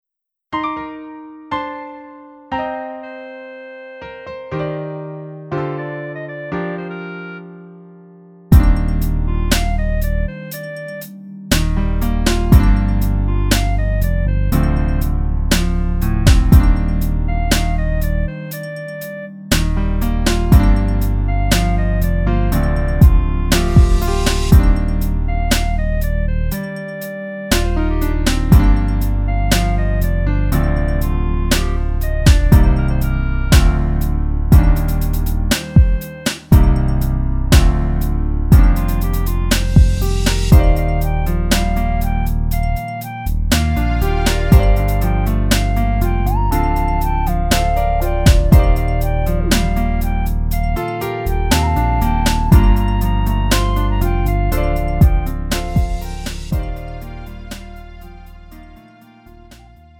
음정 원키 3:09
장르 구분 Lite MR